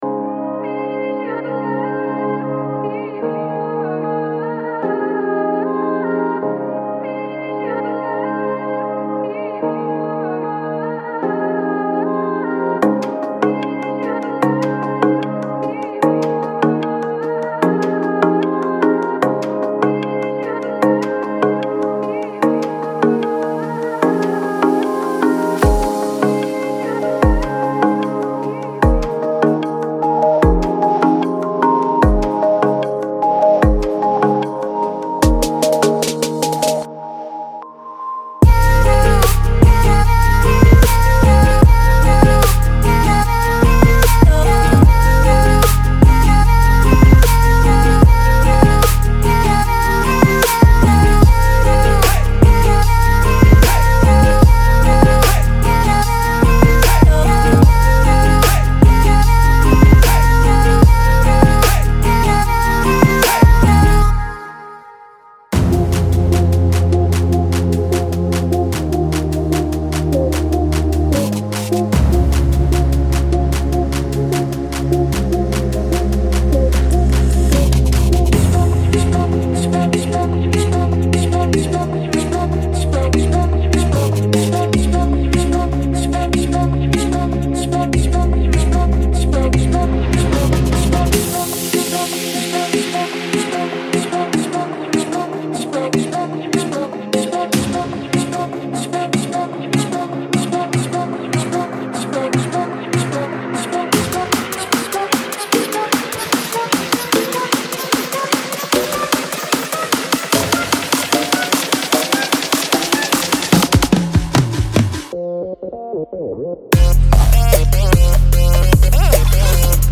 • 215个鼓采样